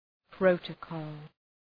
Προφορά
{‘prəʋtə,kɔ:l}
protocol.mp3